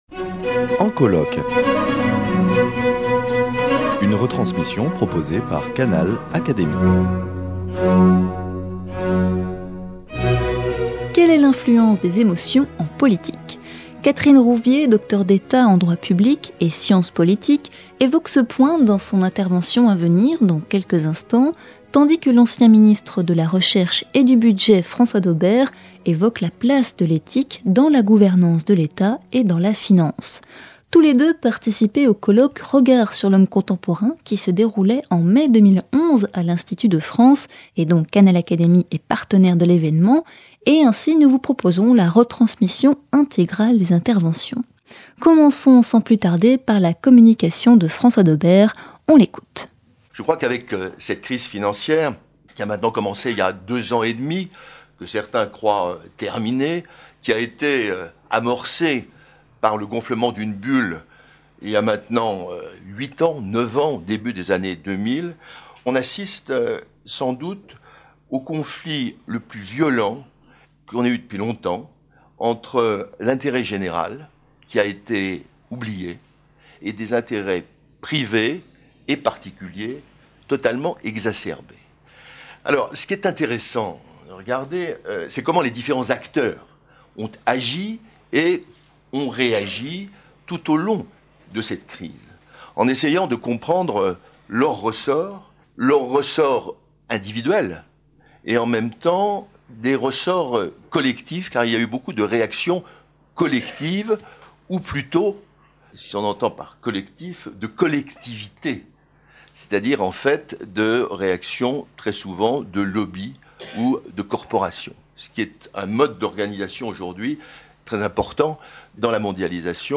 Tous les deux participaient au troisième et dernier colloque Regard sur l’homme contemporain qui se déroulait en mai 2011 à l’Institut de France. Retransmission.